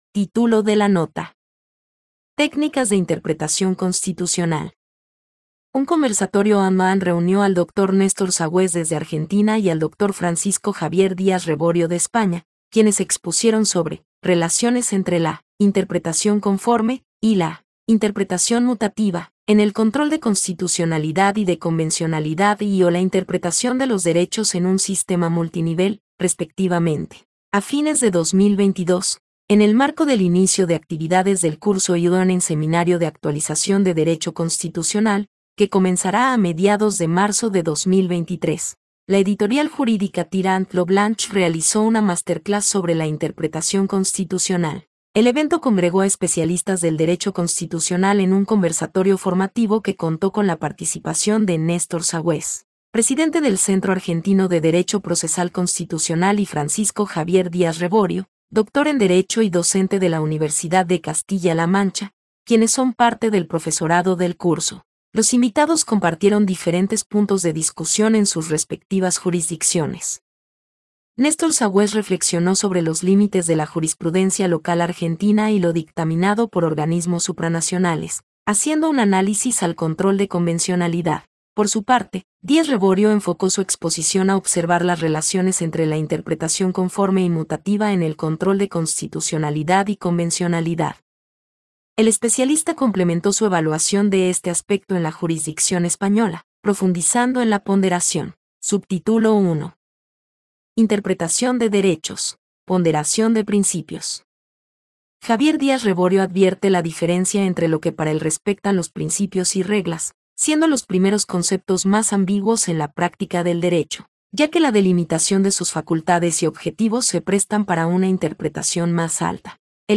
Masterclass